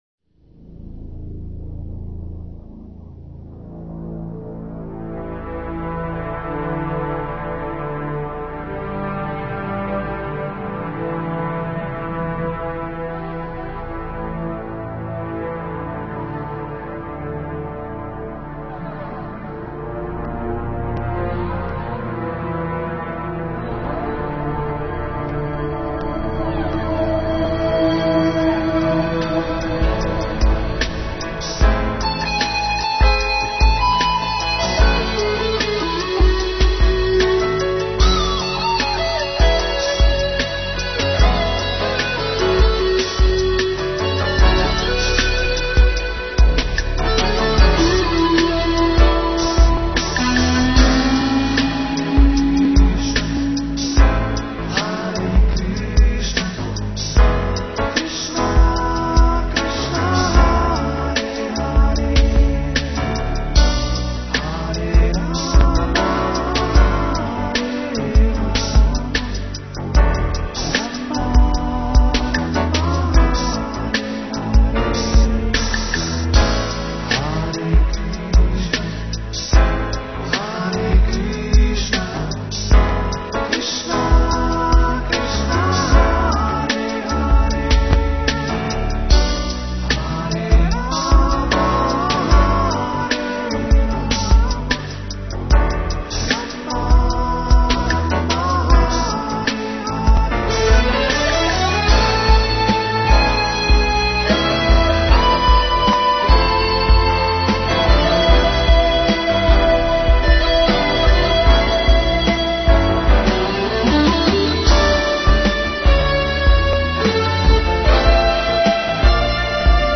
Pop Genre